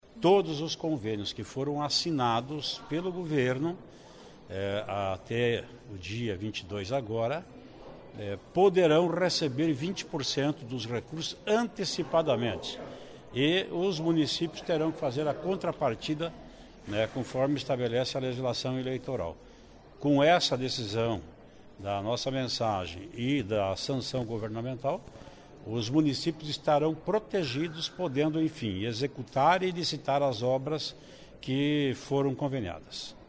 Ouça na entrevista  o que Traiano  diz sobre a importância dessa sanção.
(Sonora)